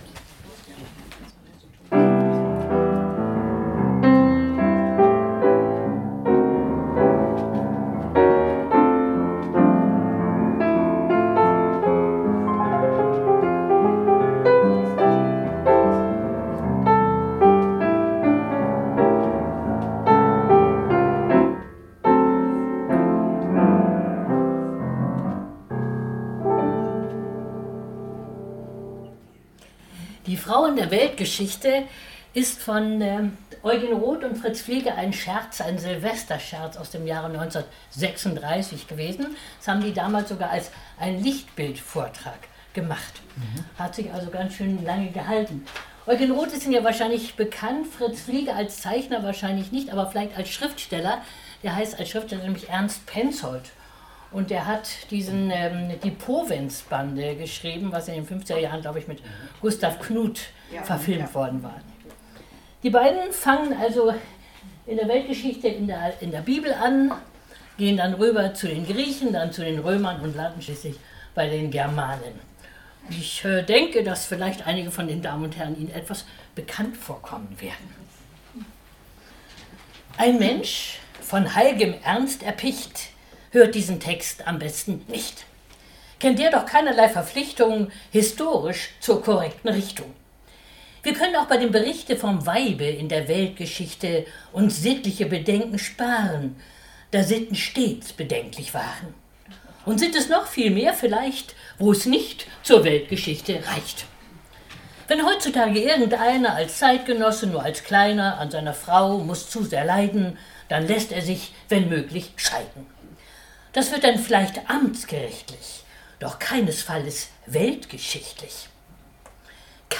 Mitschnitt einer öffentlichen Veranstaltung